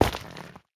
Minecraft Version Minecraft Version 1.21.5 Latest Release | Latest Snapshot 1.21.5 / assets / minecraft / sounds / block / shroomlight / step6.ogg Compare With Compare With Latest Release | Latest Snapshot
step6.ogg